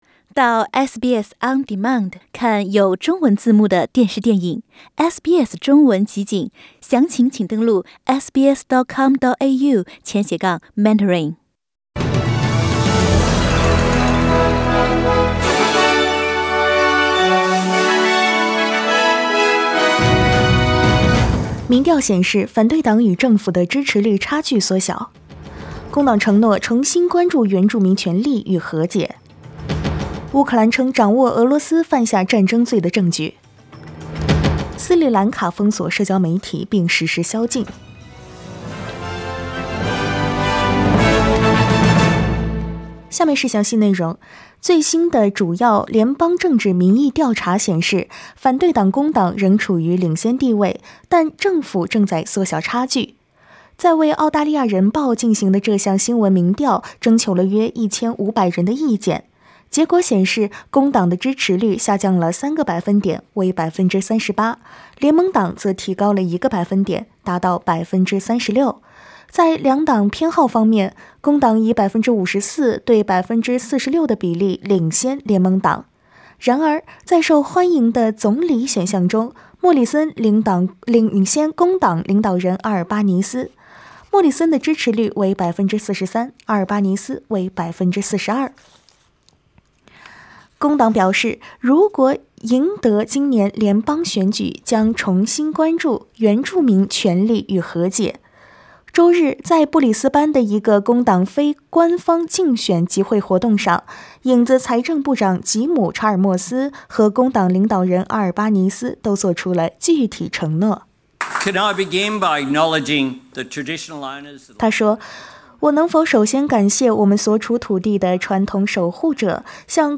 SBS早新闻（4月4日）
SBS Mandarin morning news Source: Getty Images